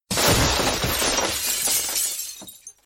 breakingGlass.ogg